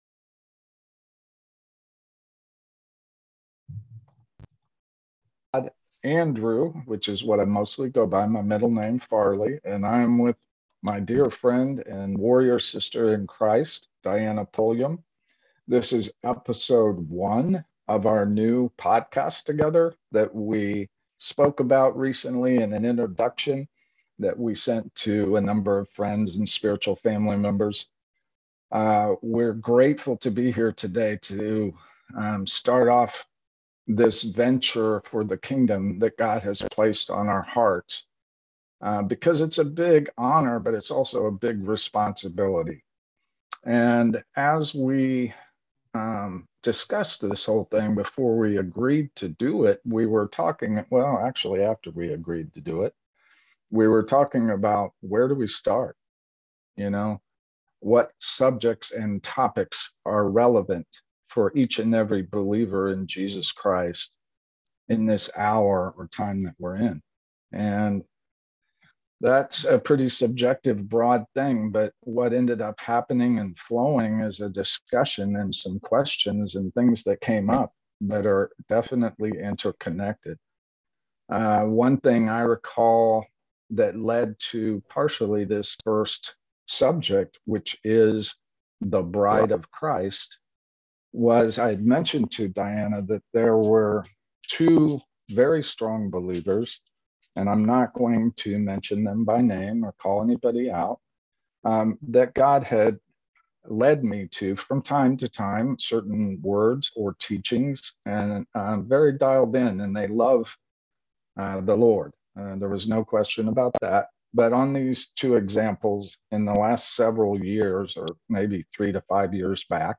Tribulation-Now Interview (The Narrow Gate)
This is my portion of an interview given October 25, 2021, on Tribulation-Now Radio. I discuss the instructions the Lord’s giving to prepare His people for the days ahead, as well as where He’s showing we are in the spiritual realm of things.